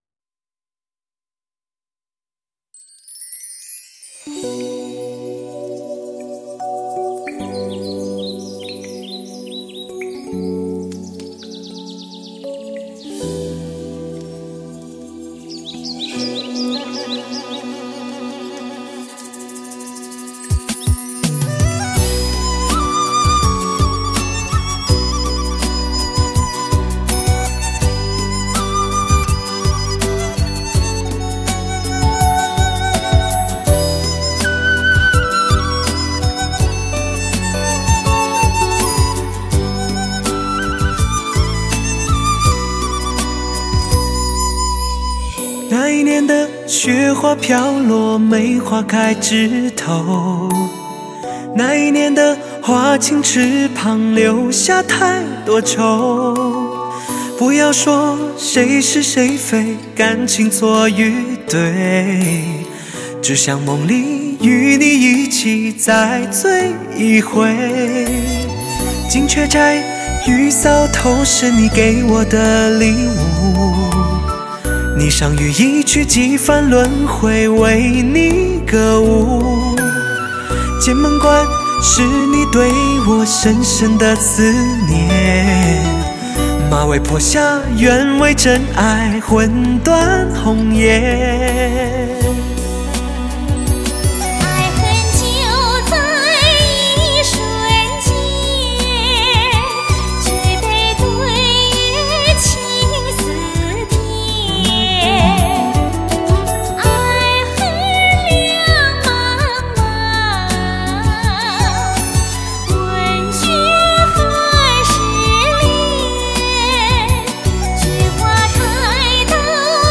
360°极致环绕
顶级最具视听效果的发烧原音，试机宝贝中的典范